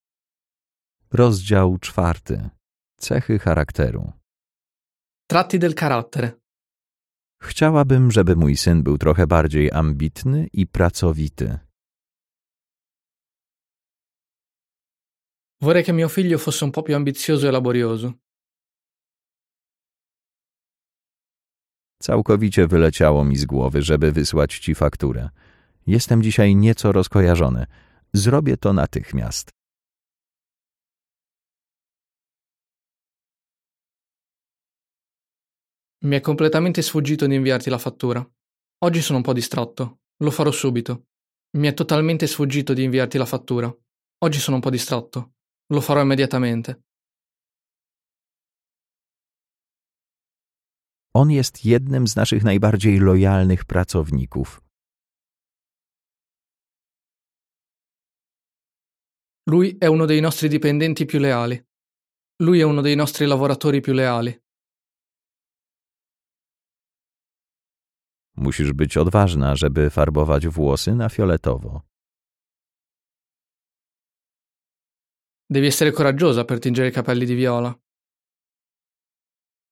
Co więcej, do książki dołączony jest kurs audio z nagraniami zdań w wykonaniu native speakera.
Regularne słuchanie włoskich wersji zdań pozwala oswoić się z brzmieniem języka, intonacją i rytmem wypowiedzi.